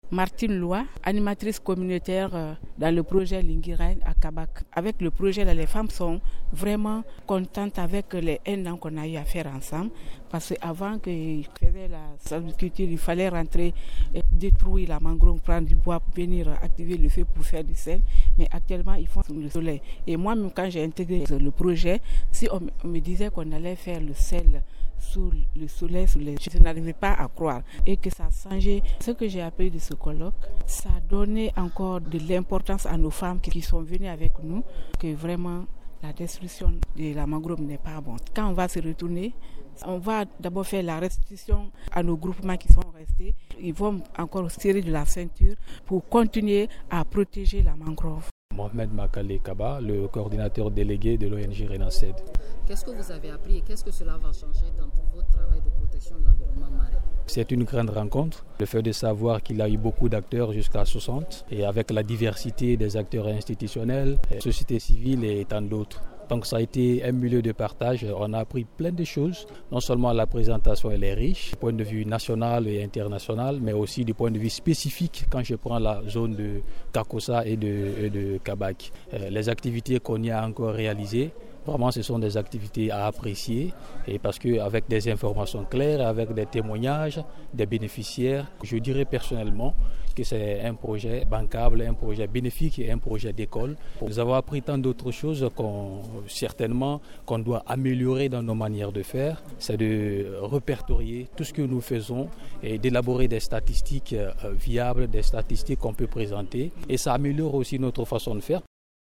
PARTICIPANTS-COLLOQUE-LINGUIRA-COYAH.mp3